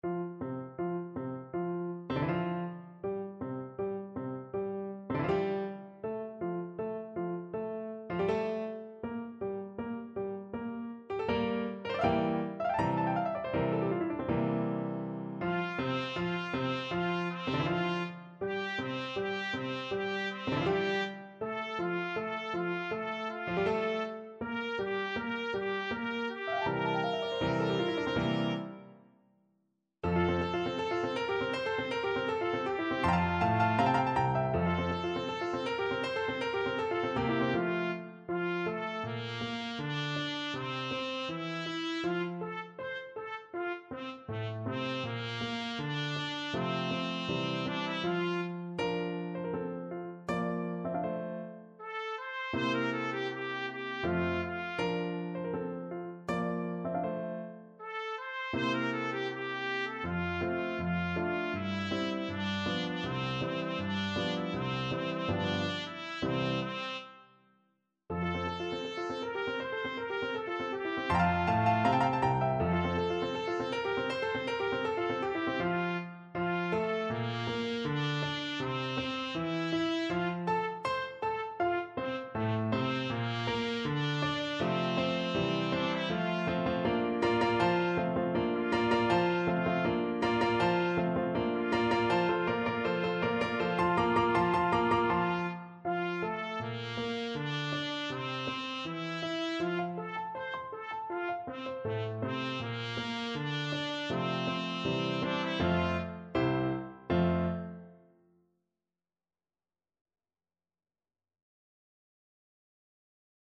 Classical Mozart, Wolfgang Amadeus Notte e giorno from Don Giovanni Trumpet version
F major (Sounding Pitch) G major (Trumpet in Bb) (View more F major Music for Trumpet )
4/4 (View more 4/4 Music)
Molto allegro =160
Trumpet  (View more Intermediate Trumpet Music)
Classical (View more Classical Trumpet Music)
mozart_notte_e_giorno_TPT.mp3